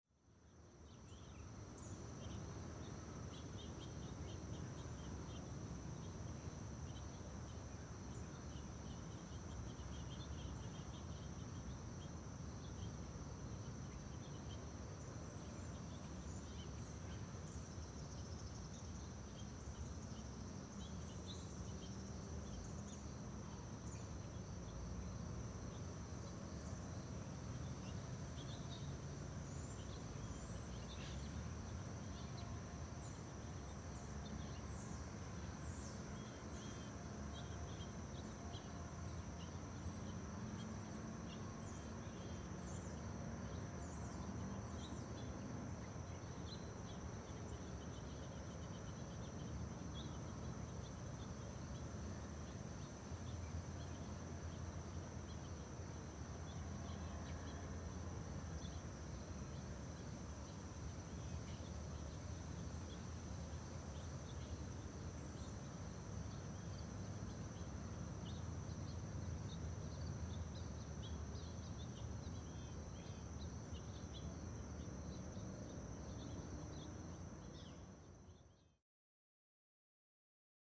Cemetery Ambience ( Arlington National ), Morning, Light Birds And Insects, Distant City Rumble.